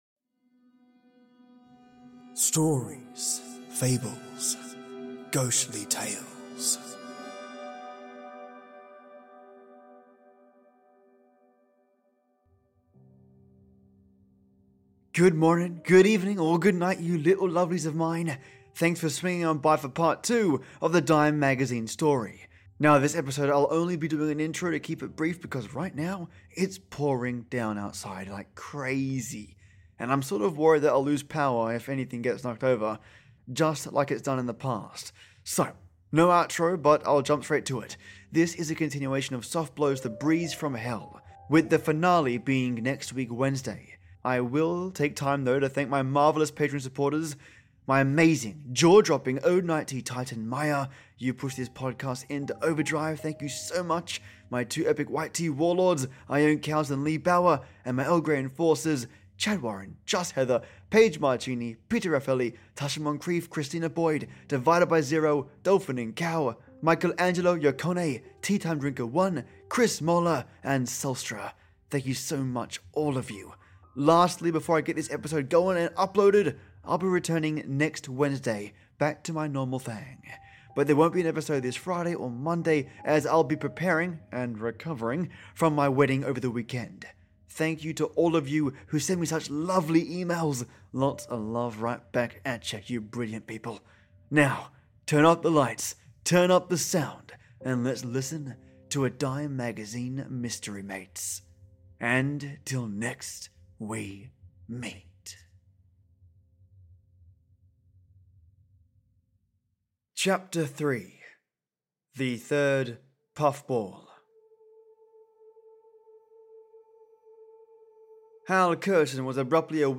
Thanks for swinging on by for Part 2 of the Dime Magazine story. Now this episode I’ll be only doing an intro to keep it brief, because right now, it’s pouring down outside, like crazy.